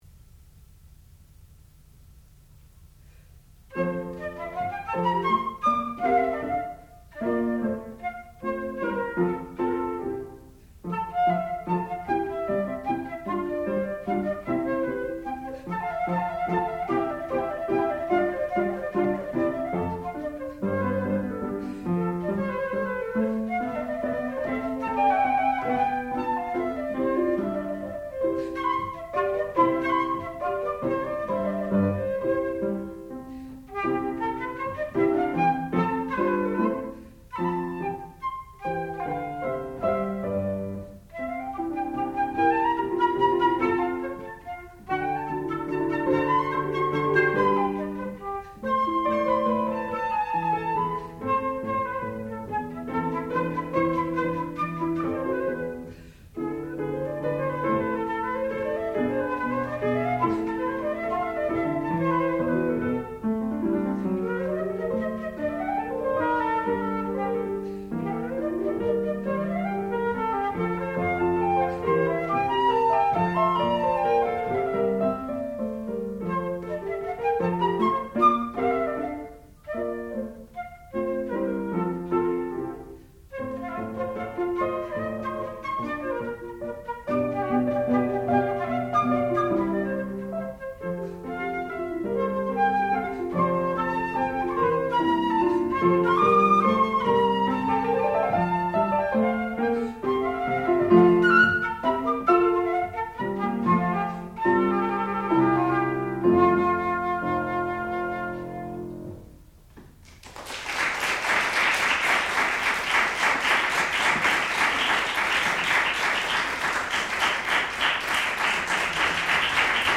Sonata for flute and piano
sound recording-musical
classical music
Advanced Recital